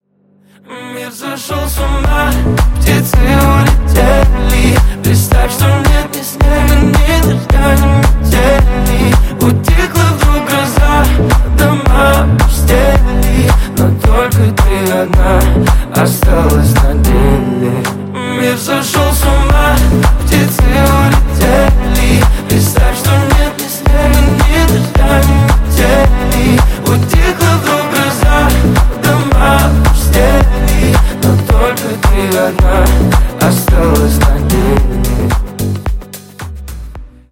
• Качество: 128, Stereo
поп
мужской вокал
красивые